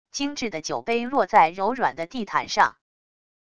精致的酒杯落在柔软的地毯上wav音频